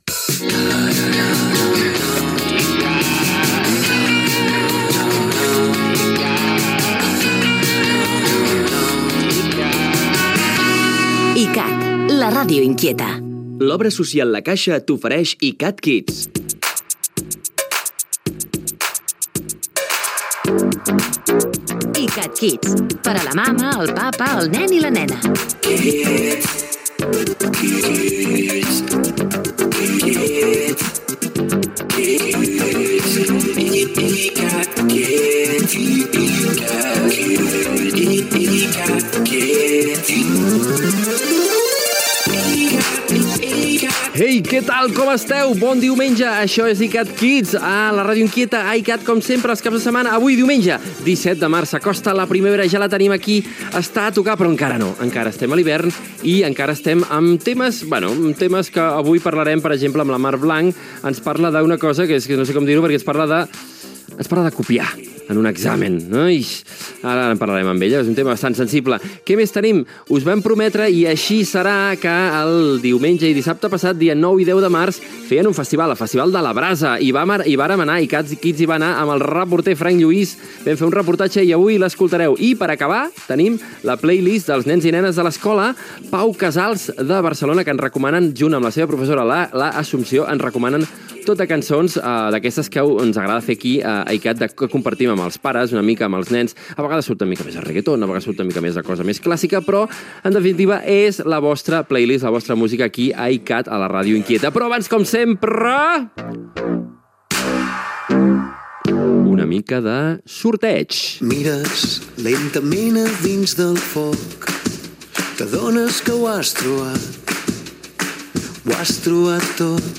Indicatiu de la ràdio, careta del programa, presentació, sumari de continguts, tema musical i concurs
Entreteniment